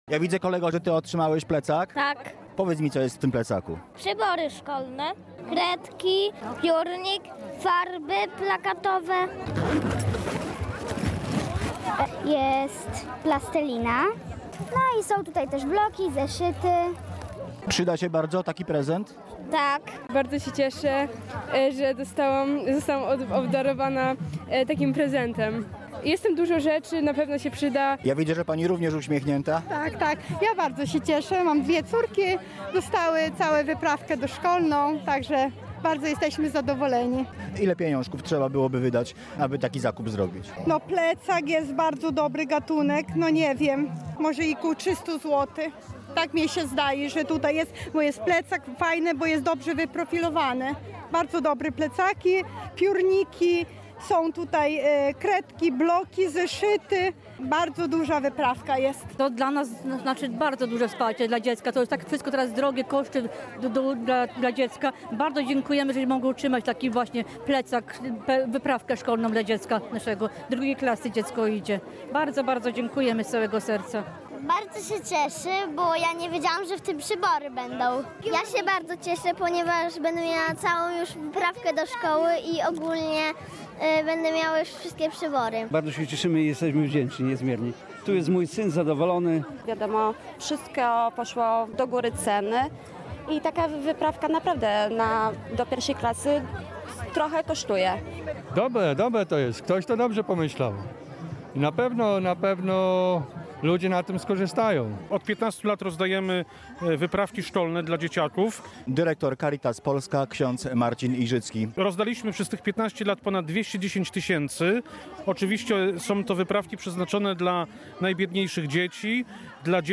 15 tysięcy plecaków z przyborami szkolnymi trafi do dzieci z niezamożnych rodzin w ramach Akcji Caritas” Tornister Pełen Uśmiechów”. Jej ogólnopolski finał odbył się dziś w Starej Birczy w powiecie przemyskim.
Relacja